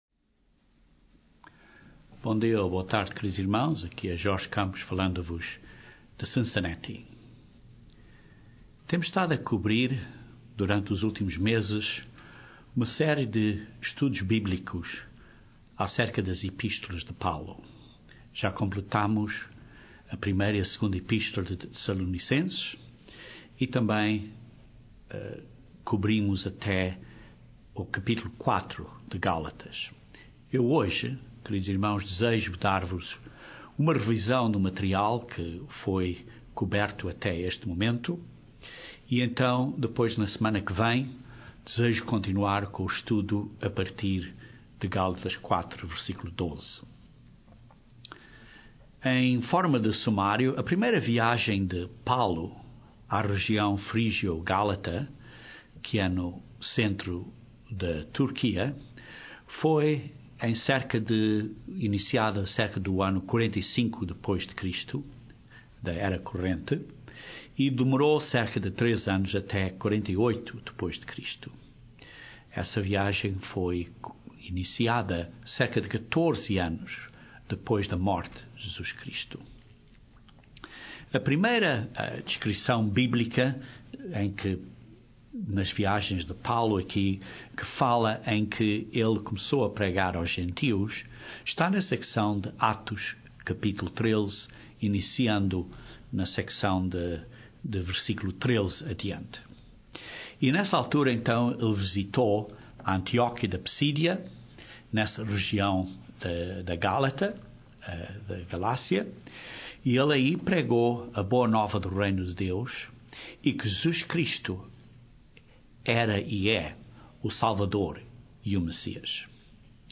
Quais foram as questões que Paulo confrontou com os Cristãos que tinham sido convertidos recentemente? Essas questões foram básicamente de dois tipos gerais. Este sermão cobre este assunto atravéz duma revisão do material até Gal:4:11.